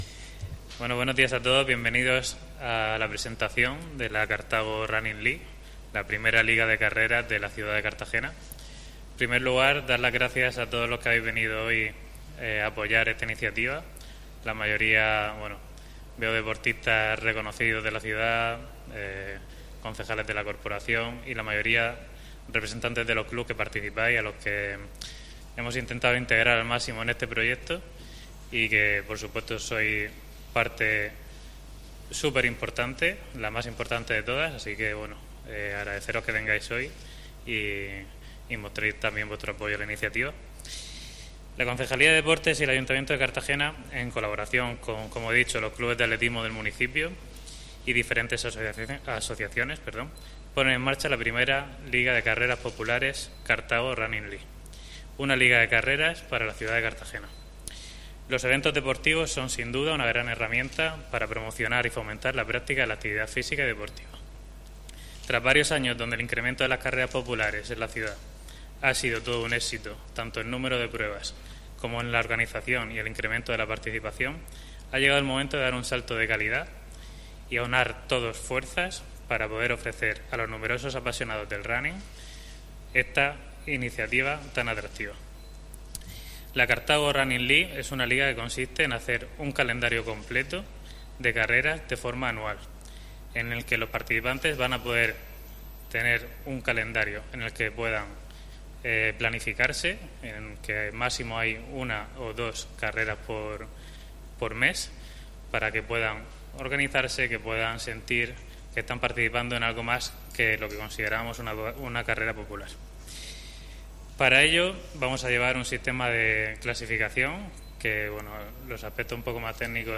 Presentación de la Carthago Running League, I Liga de carreras populares de Cartagena